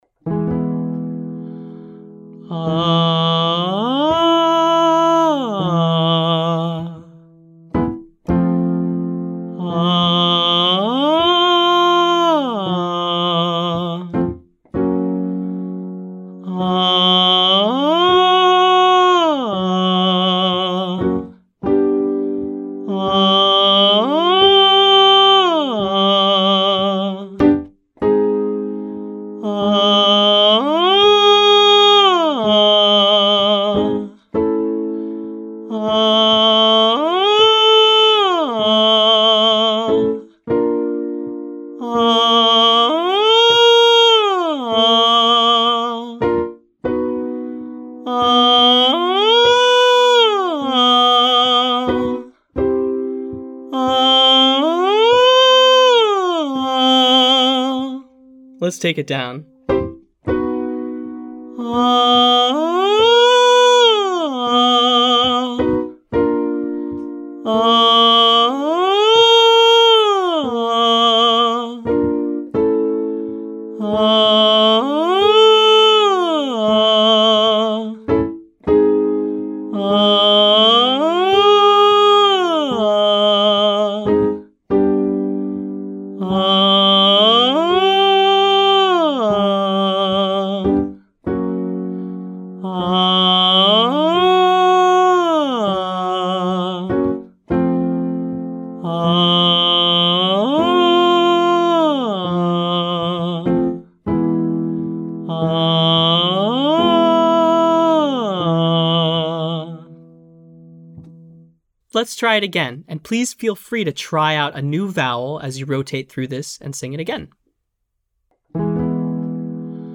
Finally, we’ll end on a fairly open vowel like AH, or UH.